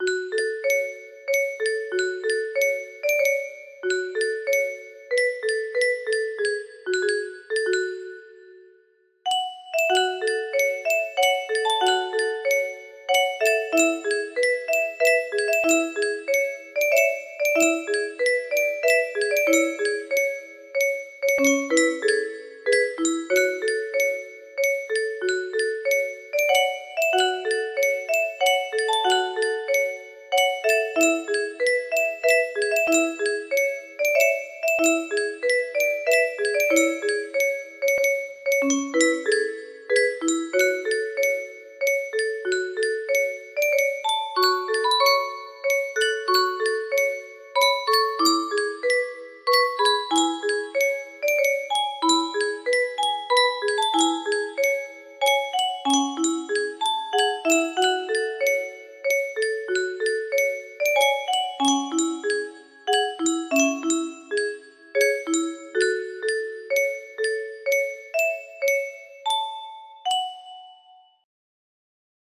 Clone of OdA VTM music box melody
Full range 60